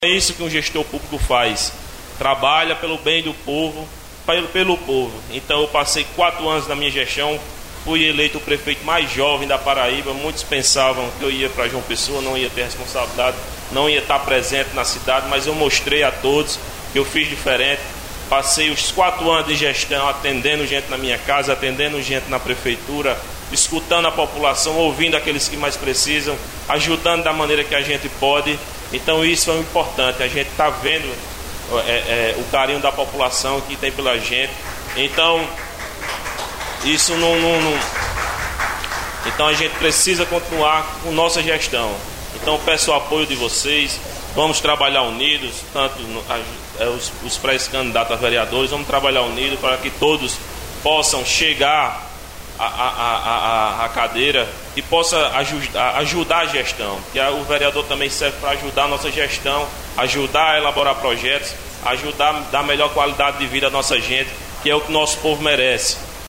CONVENÇÃO EM NOVA OLINDA: Diogo homologa sua candidatura e diz ter vencido preconceitos ao “mostrar serviço” sendo o prefeito mais novo da Paraíba